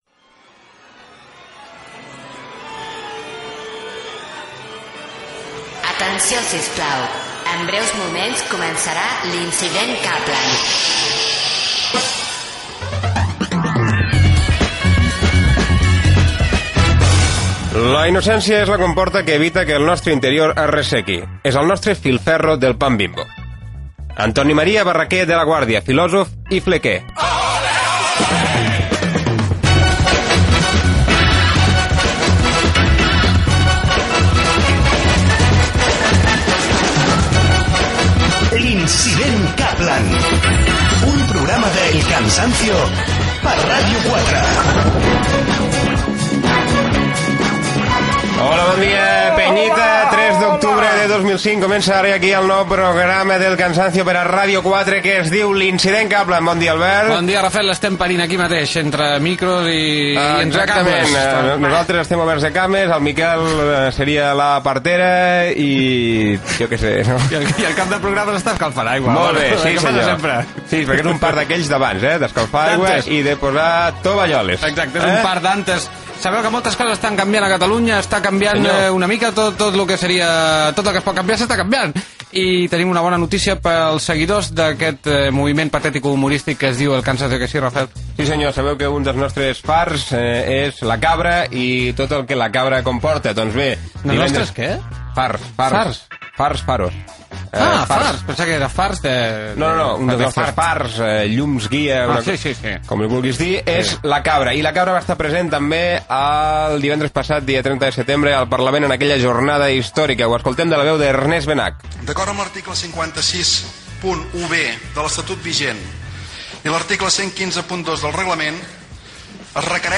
Indicatiu del programa, secció "Salvem Catalunya", publicitat fictícia.
Gènere radiofònic Entreteniment